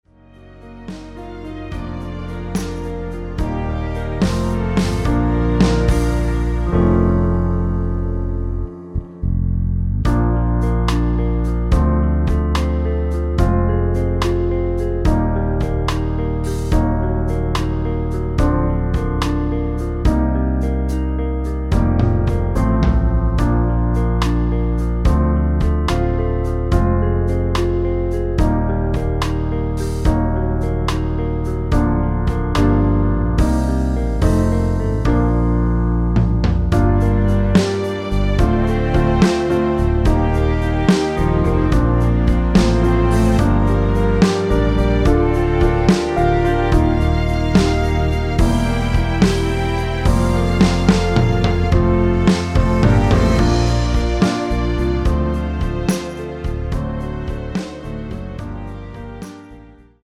원키에서(-2)내린 (1절앞+후렴)으로 진행되는 MR입니다.
Bb
앞부분30초, 뒷부분30초씩 편집해서 올려 드리고 있습니다.
중간에 음이 끈어지고 다시 나오는 이유는